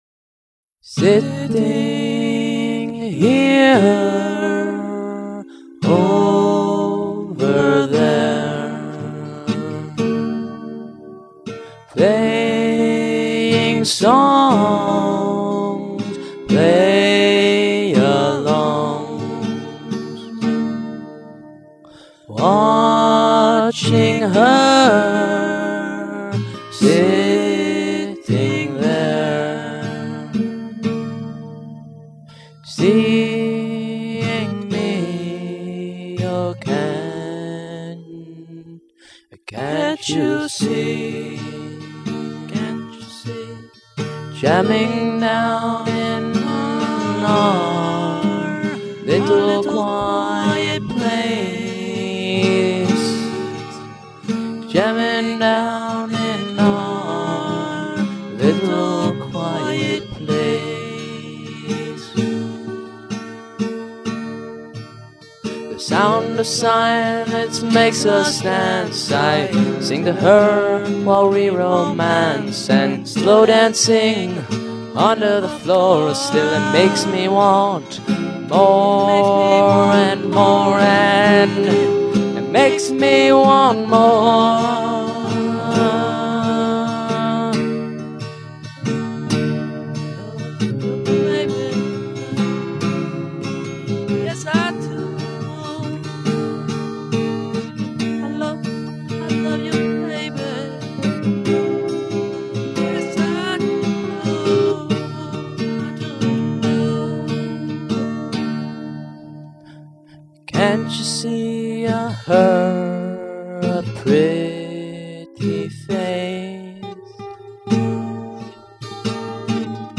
All acoustic guitar and singing.